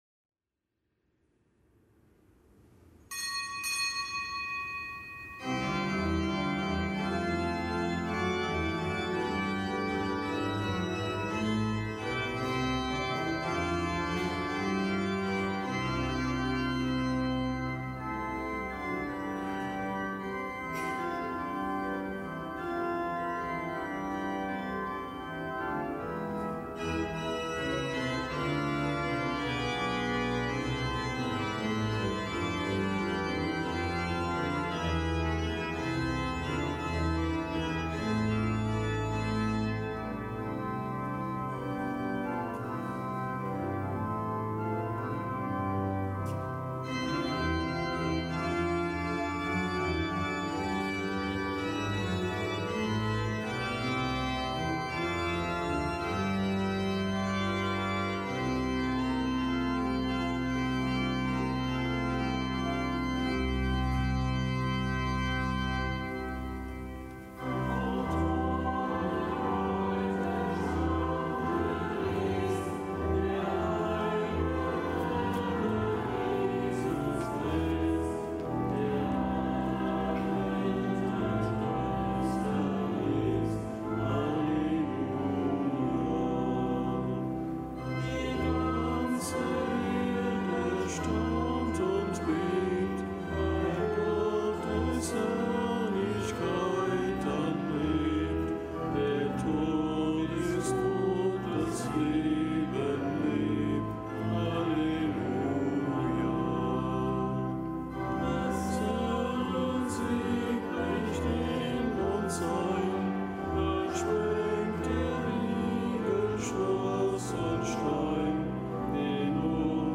Kapitelsmesse aus dem Kölner Dom am Dienstag der Osteroktav.